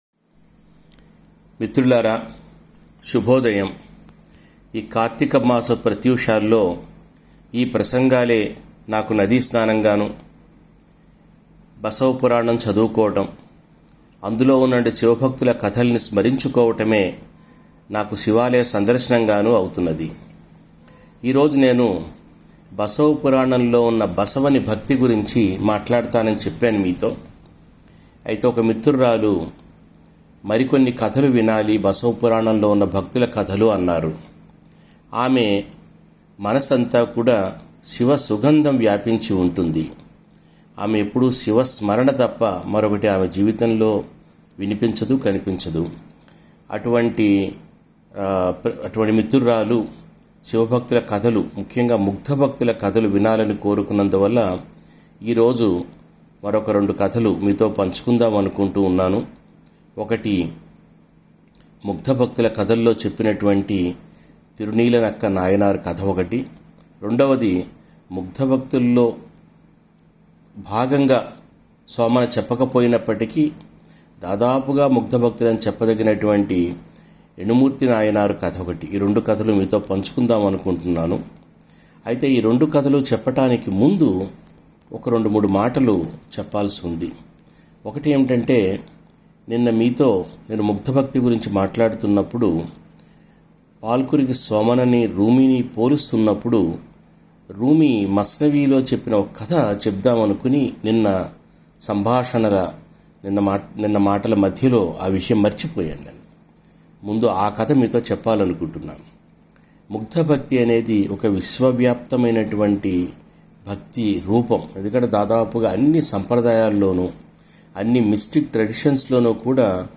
అటువంటి ఇద్దరు భక్తుల కథల గురించి ఈ రోజు ప్రసంగం.